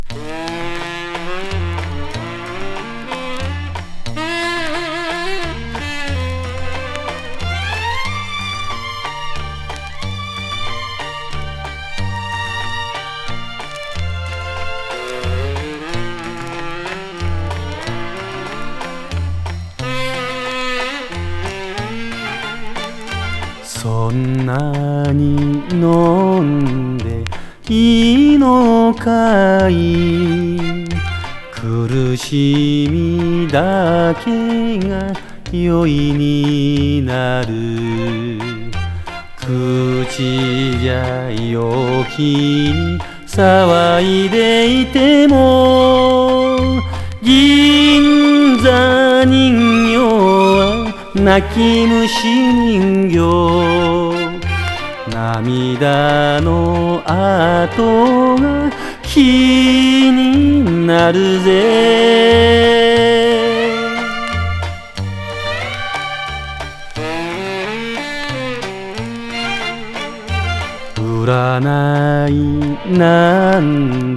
朴訥とした歌声が魅力のエスノ・ムードもの。自主銀座チューン。